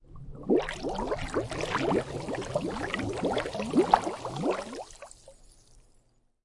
沸腾的水
描述：关闭一小壶开水的记录。
标签： 气泡 鼓泡 沸腾
声道立体声